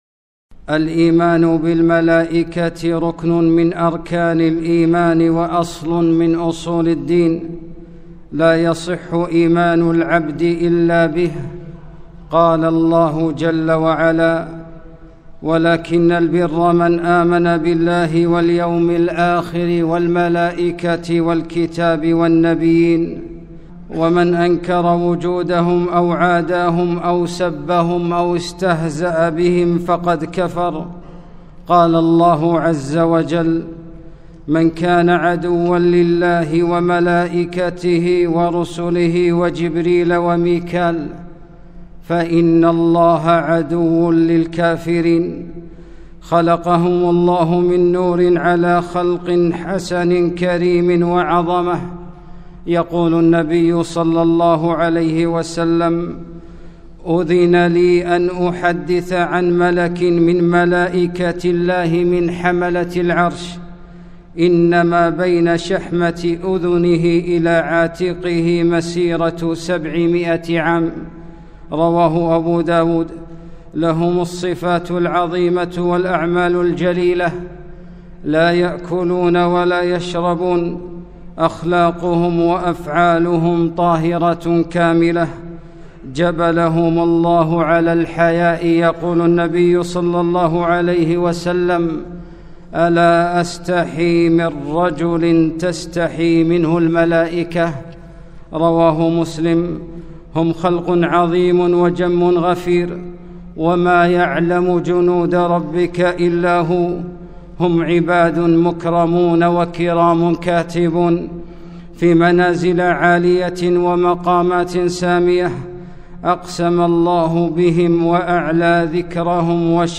خطبة - ملائكة الرحمن